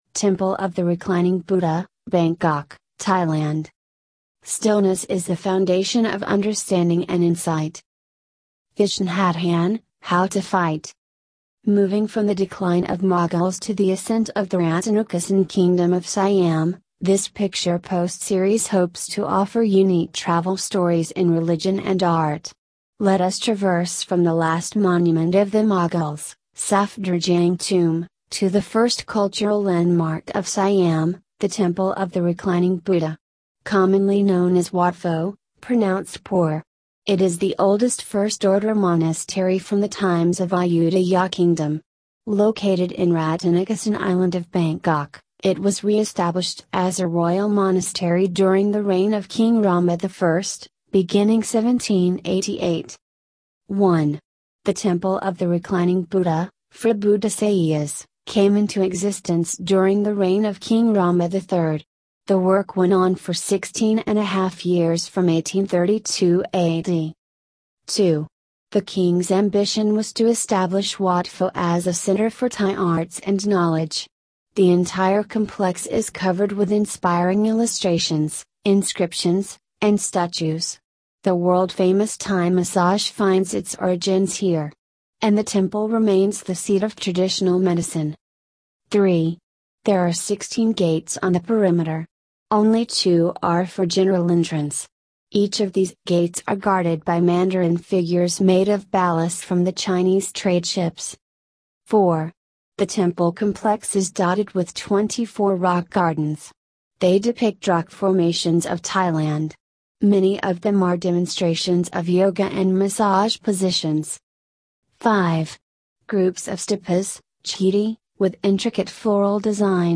Commonly known as Wat Pho (pronounced poor). It is the oldest first-order monastery from the times of Ayutthaya kingdom.